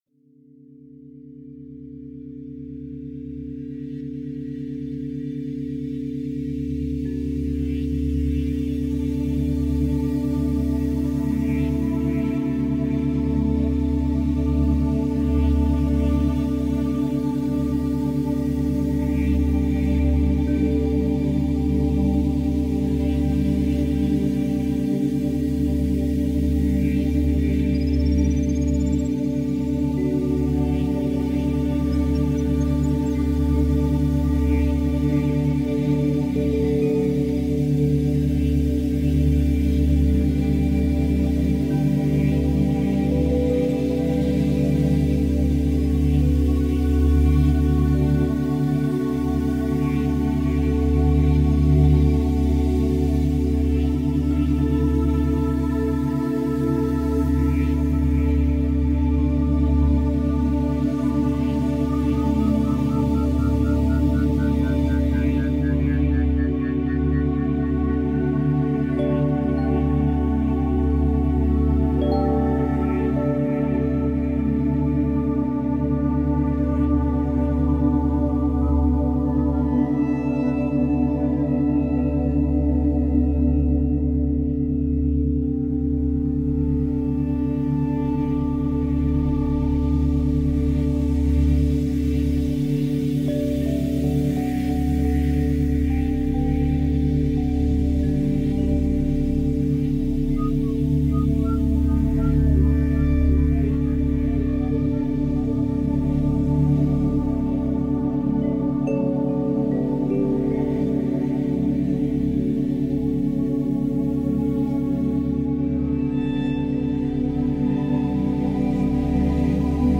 Gamma Waves – 40 Hz for Mental Performance and Memory